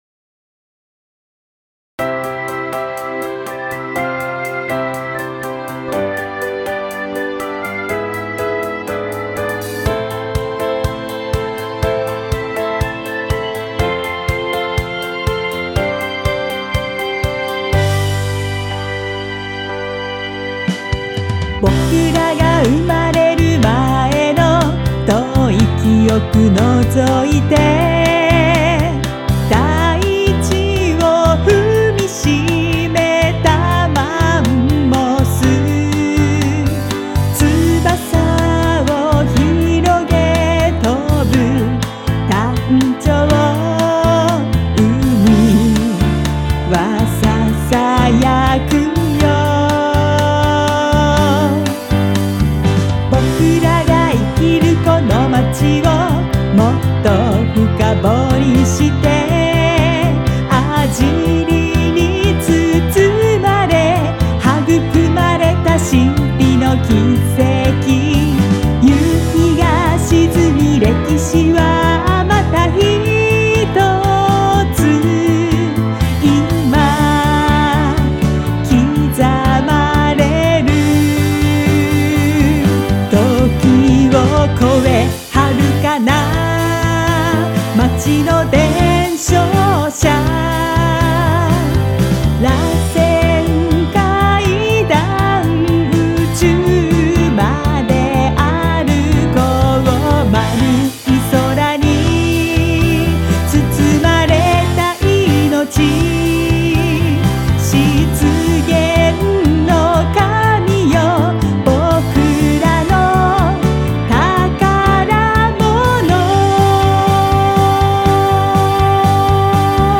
ボーカル入り楽曲（Vo.